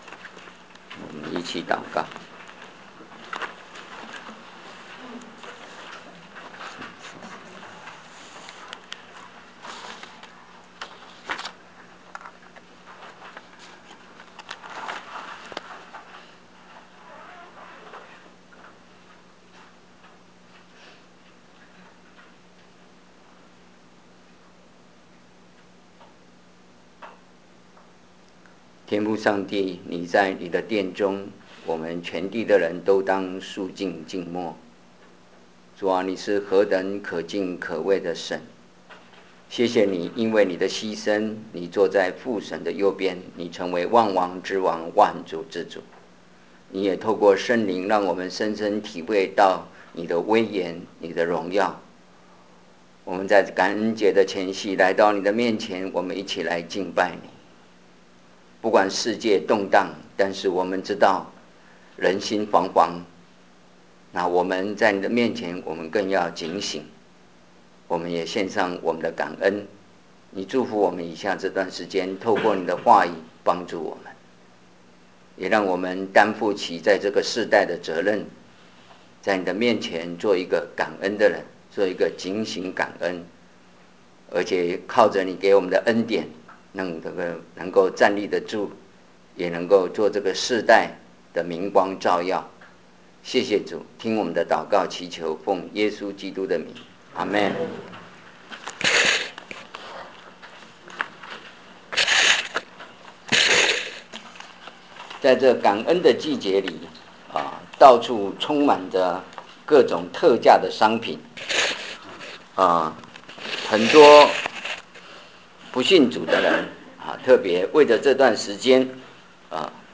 所有布道录音现都已转换成MP3格式，这样能用本网站内置播放器插件播放。